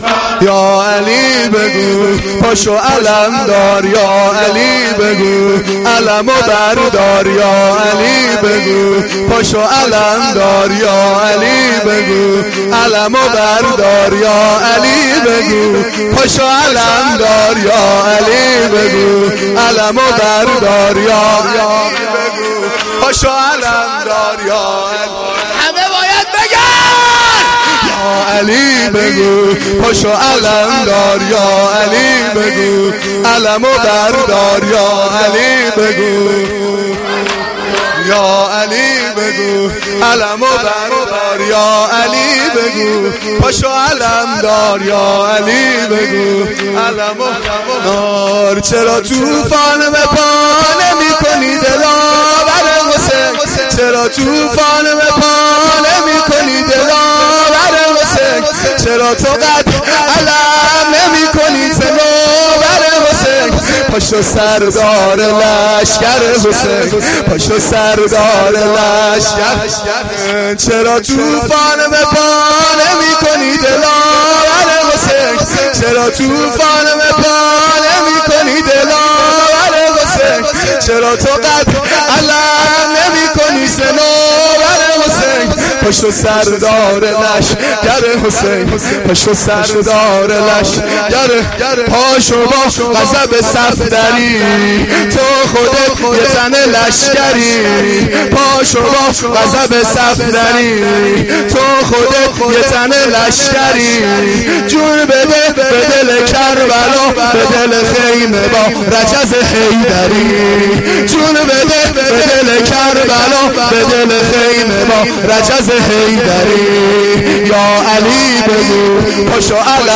مداحی
Shab-9-Moharam-5.mp3